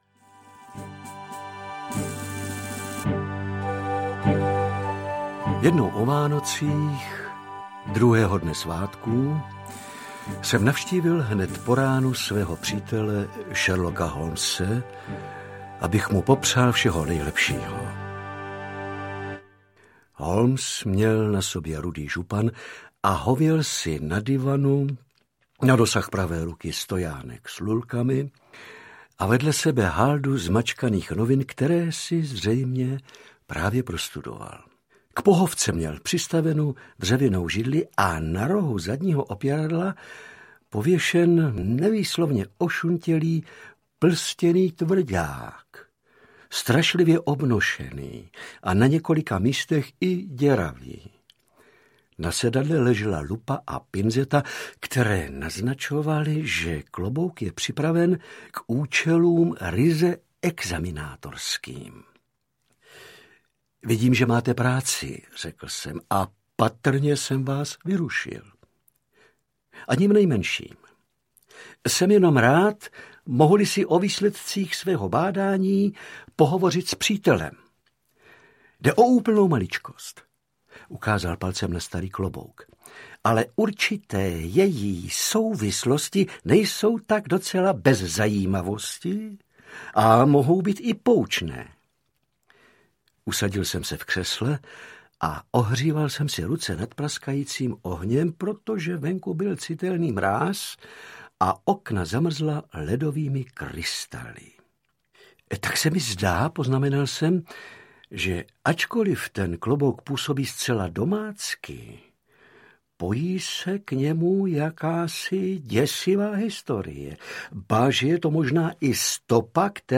Vánoční detektivky audiokniha
Ukázka z knihy
Herec Ladislav Frej propůjčil svůj hlas postavě Sherlocka Holmese již v nahrávce Pes baskervillský, na nové audioknize se k postavě nejslavnějšího detektiva všech dob vrátil a ve druhém příběhu se stává i otcem Brownem.
• InterpretLadislav Frej